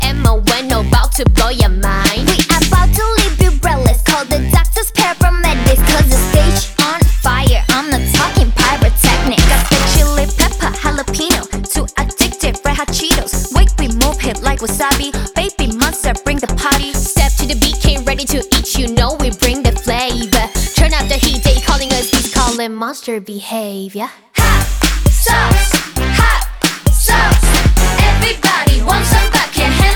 K-Pop Pop Hip-Hop Rap Korean Hip-Hop
Жанр: Хип-Хоп / Рэп / Поп музыка